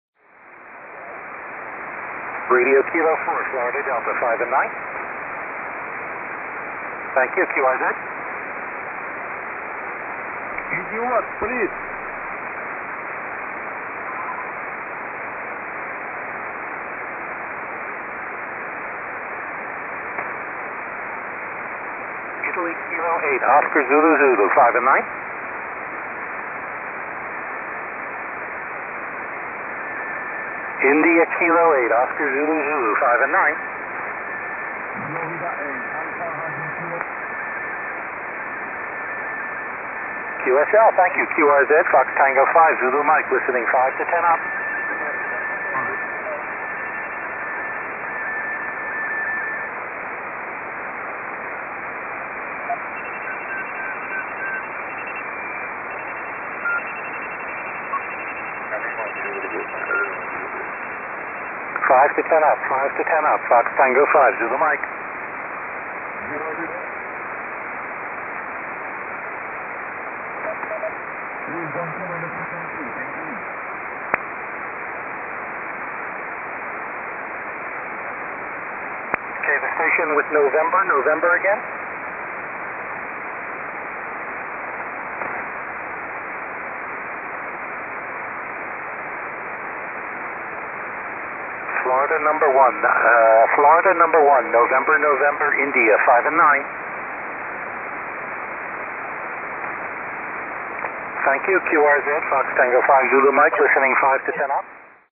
FT5ZM 10SSB (first signals)